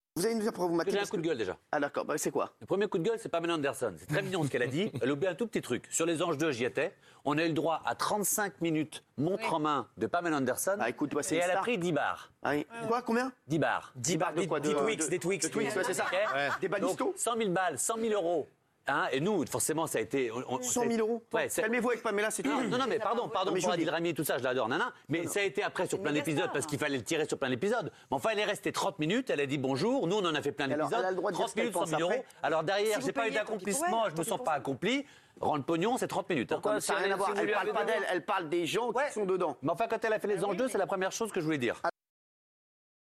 Très surpris, il a renchainé hier soir sur le plateau : « Elle a touché 100 000 euros (...) elle est restée 30 minutes pour 100 000 euros ».